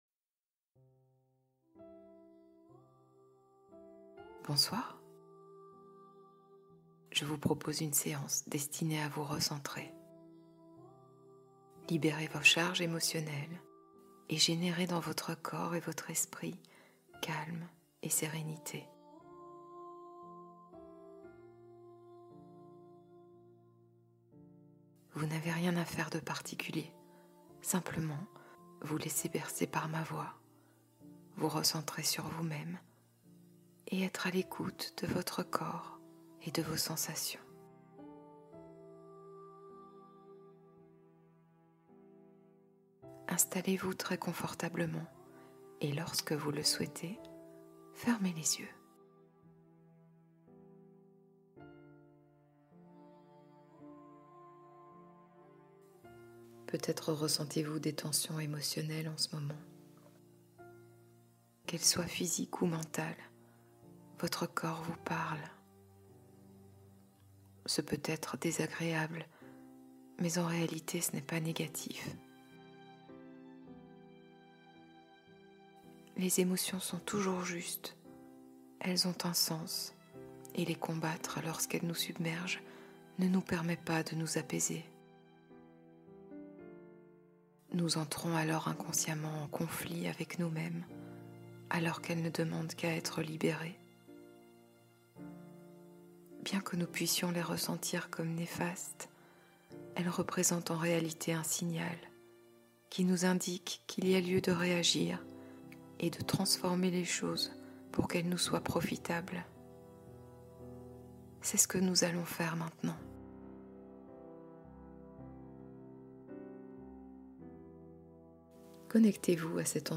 Sommeil au cœur des étoiles : hypnose réparatrice